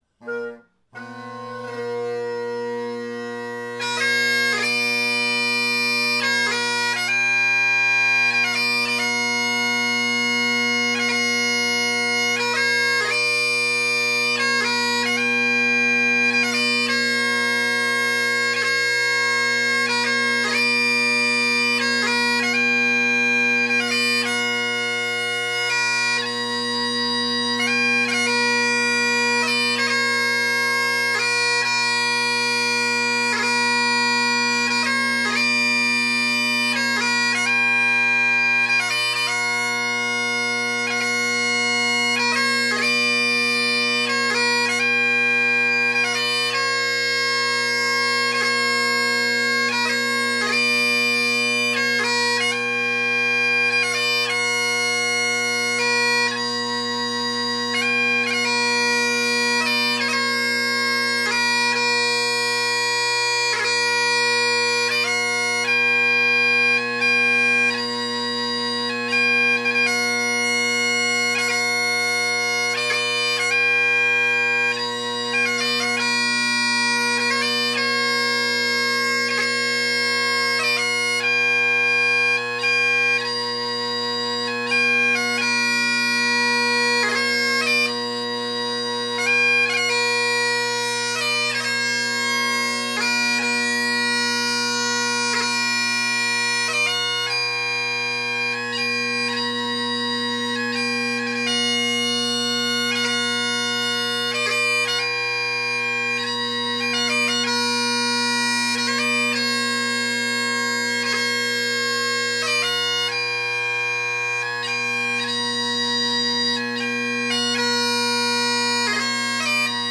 Great Highland Bagpipe Solo, Tune of the Month
a round hornpipe
the graduated tempo increase